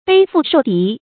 背腹受敵 注音： ㄅㄟˋ ㄈㄨˋ ㄕㄡˋ ㄉㄧˊ 讀音讀法： 意思解釋： 指前后都受到敵人的攻擊。